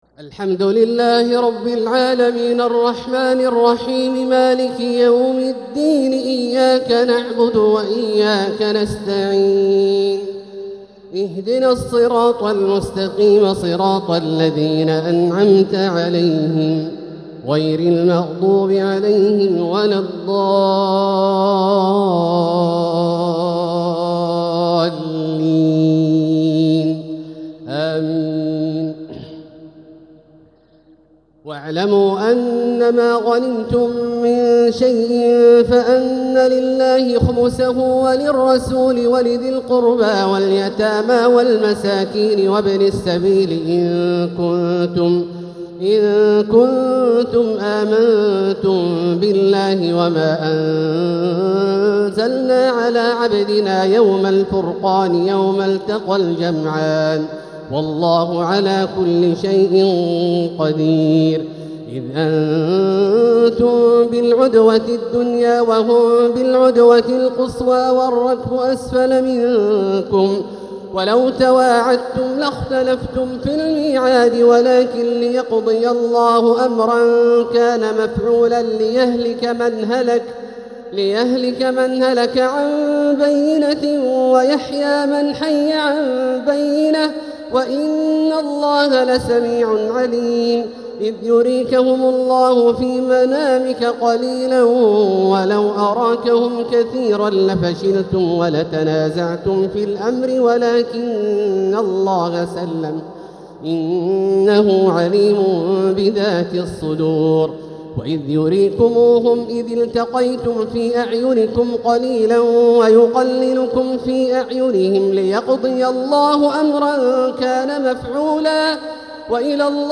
تراويح ليلة 13 رمضان 1446هـ من سورتي الأنفال (41) التوبة (1-16) | taraweeh 13th niqht Surah Al-Anfal and At-Tawba 1446H > تراويح الحرم المكي عام 1446 🕋 > التراويح - تلاوات الحرمين